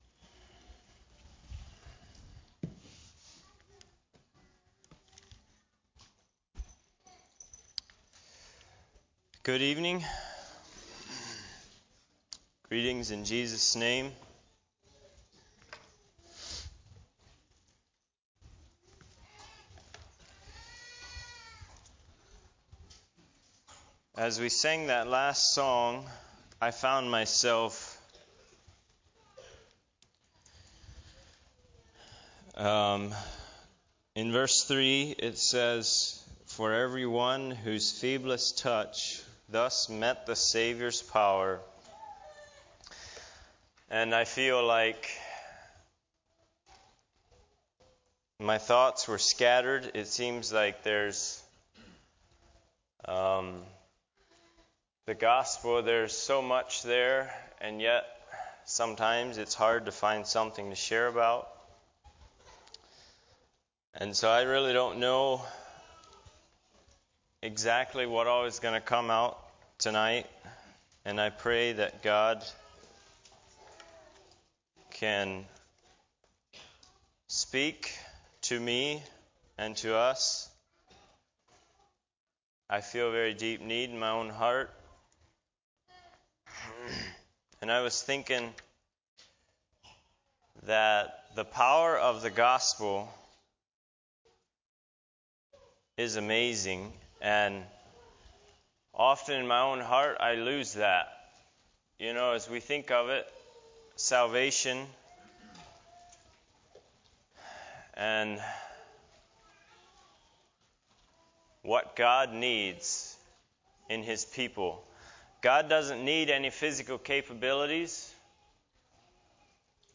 ACCF Sermons